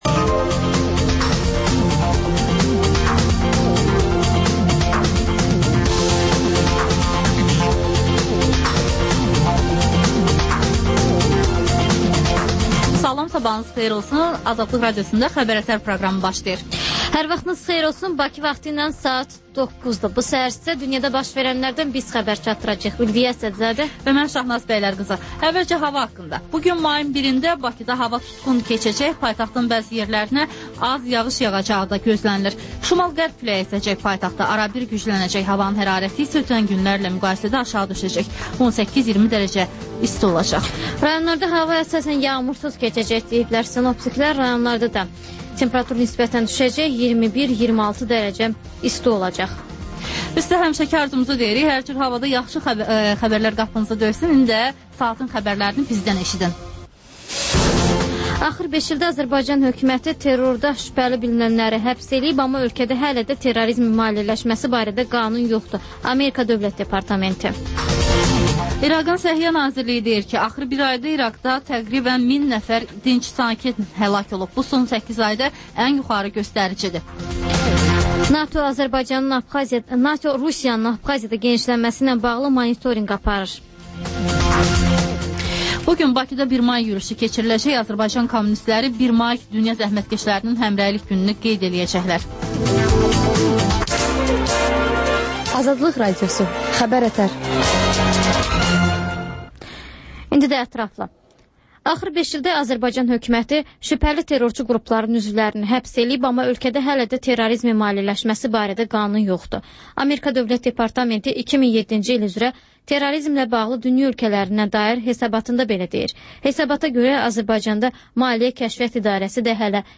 Xəbər-ətər: xəbərlər, müsahibələr, daha sonra ŞƏFFAFLIQ: Korrupsiya haqqında xüsusi veriliş.